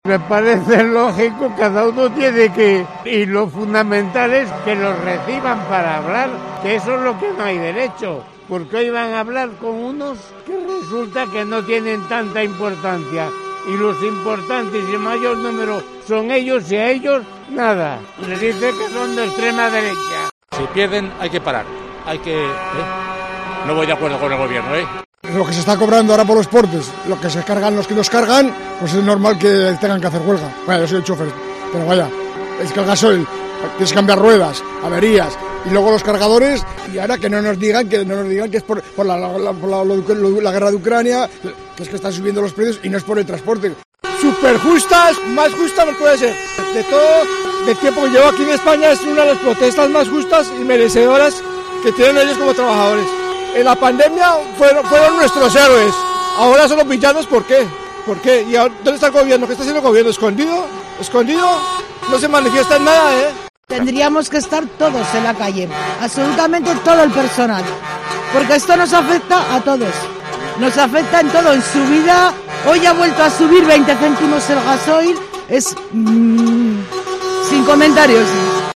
Opiniones de vecinos de Logroño sobre la huelga del transporte por carretera